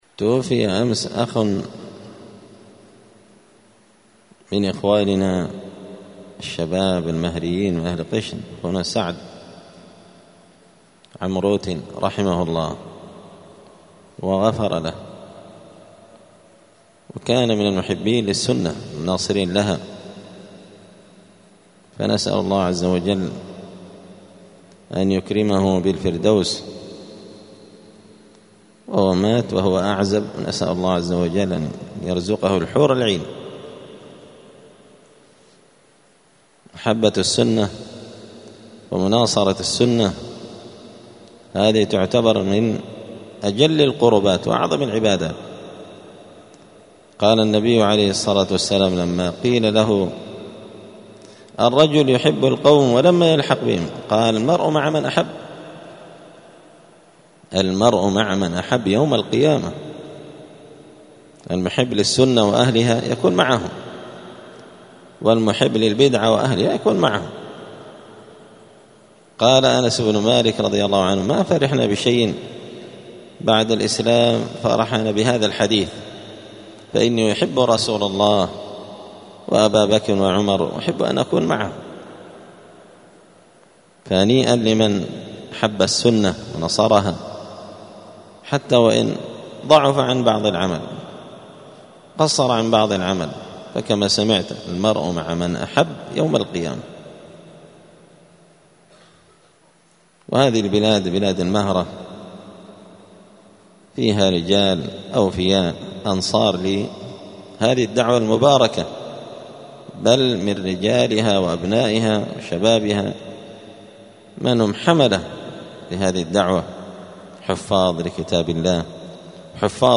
دار الحديث السلفية بمسجد الفرقان